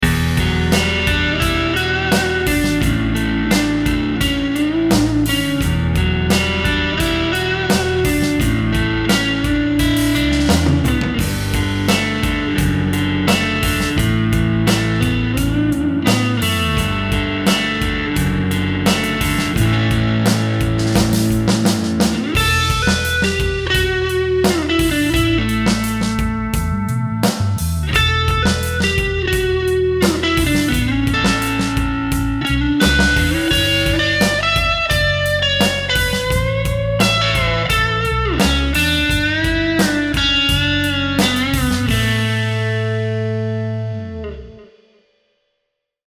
Vintage output Telecaster rhythm tone but with alnico 2 rod magnets for a sweeter, smoother treble response.
APTR-1_CRUNCH_BAND_SM